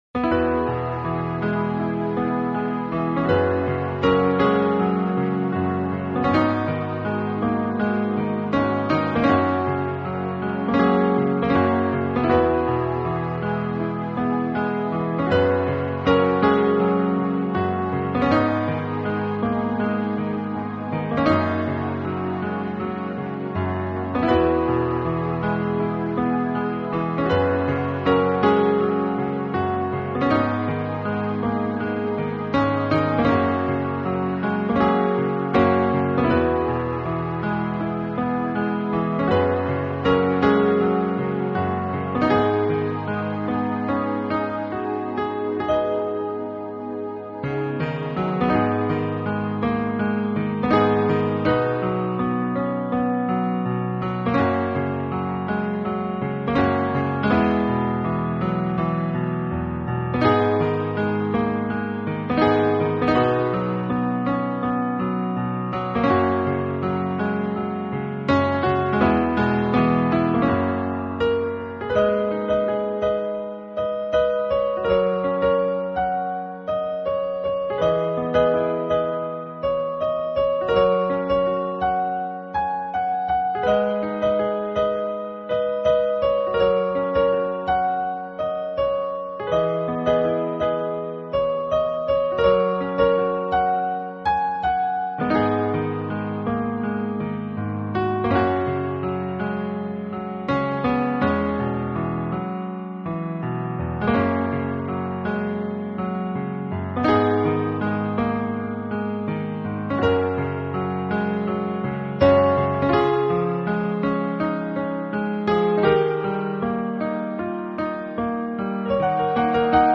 这是一套非常经典的老曲目经过改编用钢琴重新演绎的系列专辑。
而缠绵悱恻。
本套CD全部钢琴演奏，